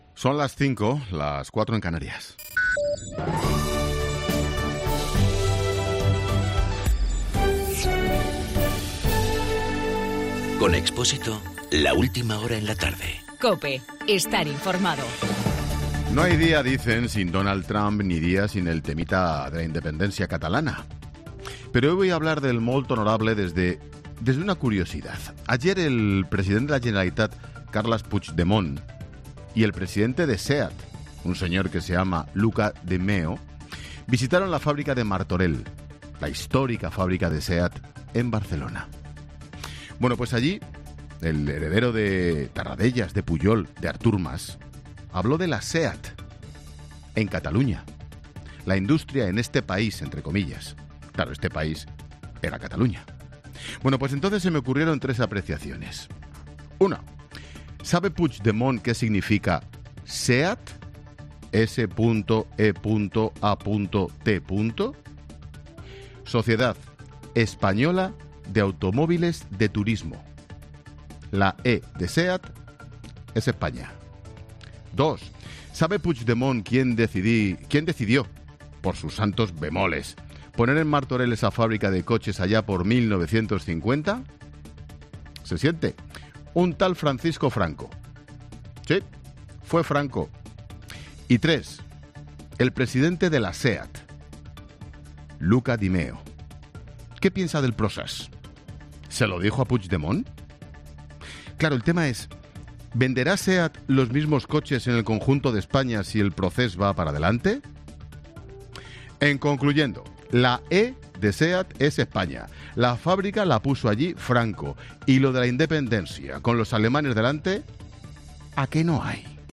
AUDIO: Monólogo 17h.